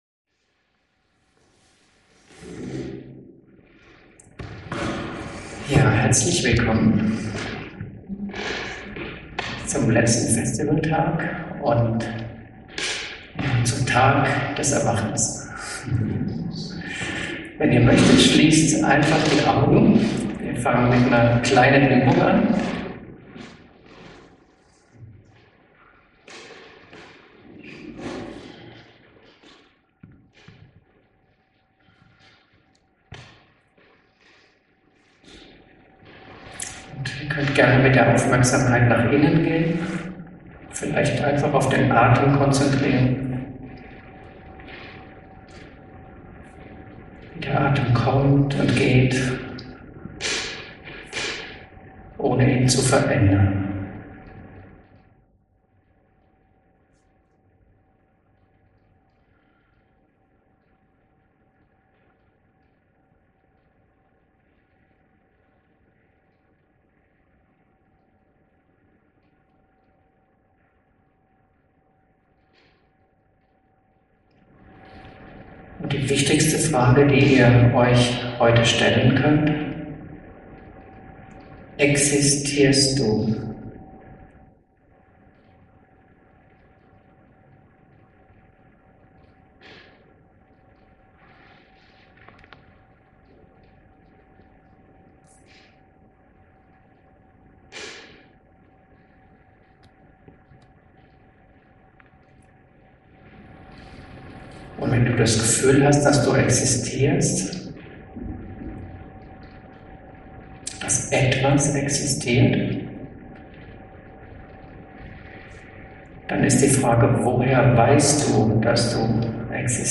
Vortrag05